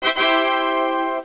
tada.au